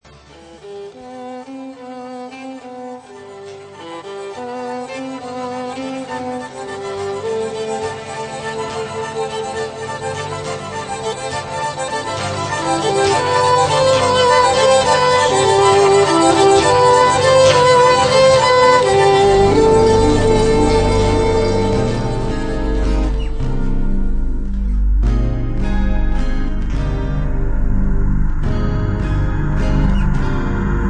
quirky idiosyncratic numbers